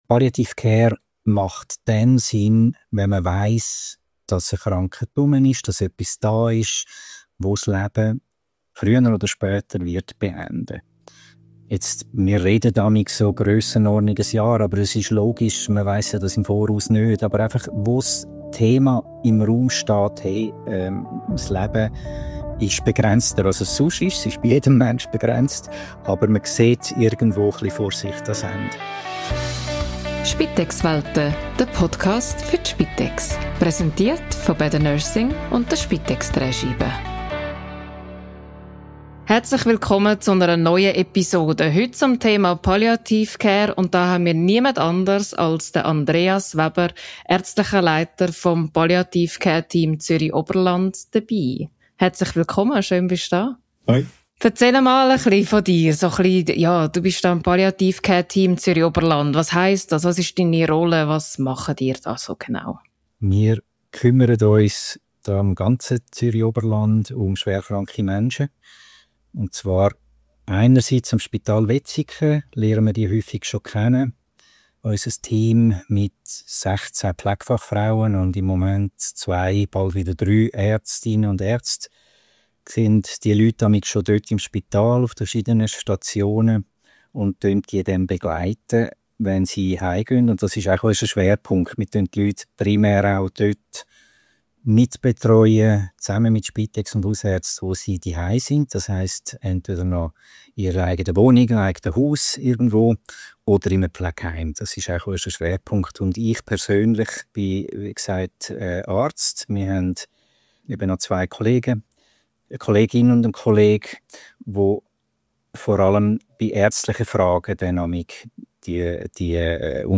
Gast